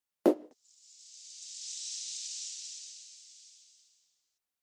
Confetti Pop Sound Effect
High quality “Confetti Pop” sound effect.
confetti-pop-sound-effect.mp3